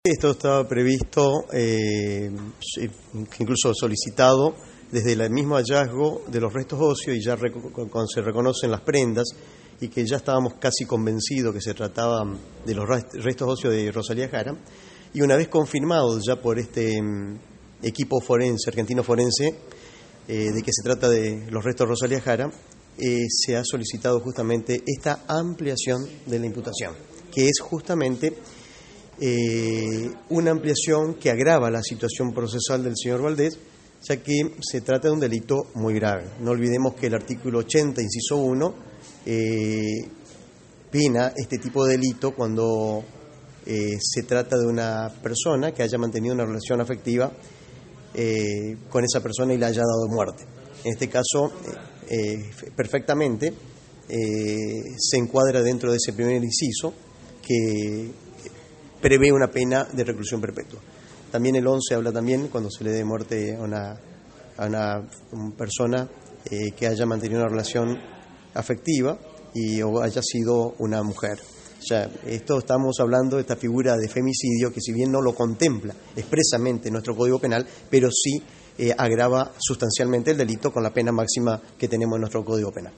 abogado querellante